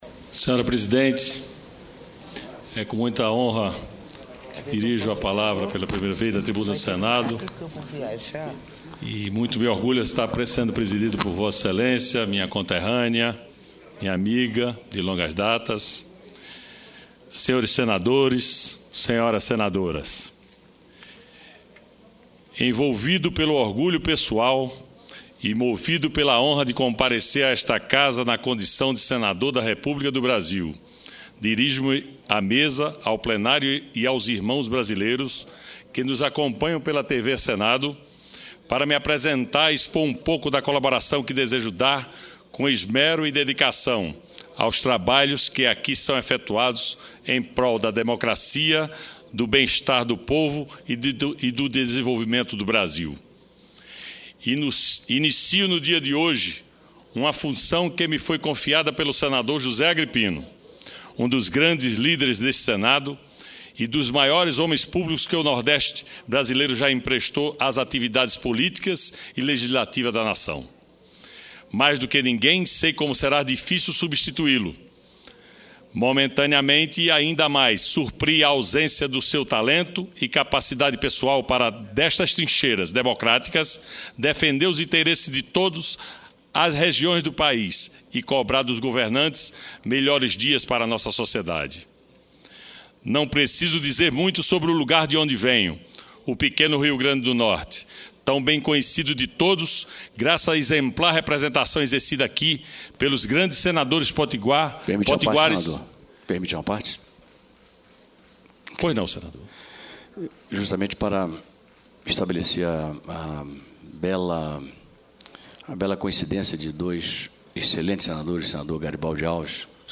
José Bezerra Júnior (DEM-RN) faz pronunciamento de posse - 1ª parte